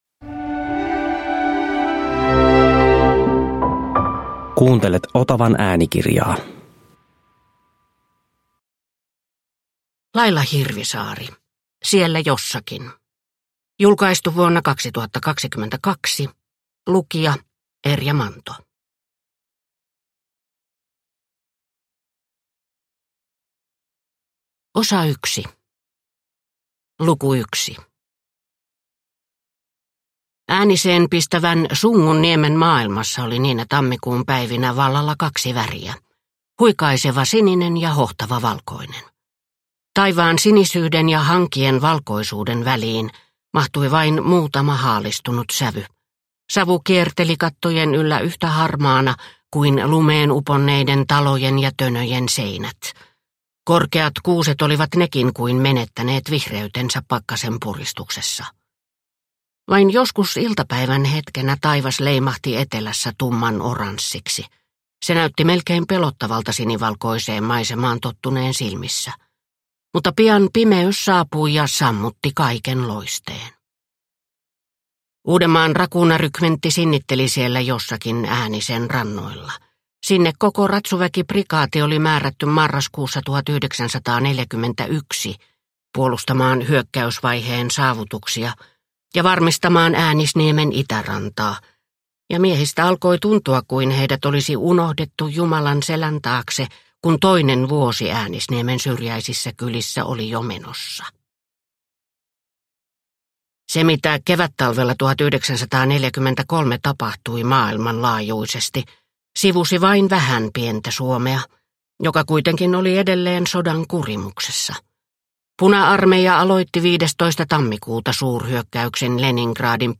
Siellä jossakin – Ljudbok – Laddas ner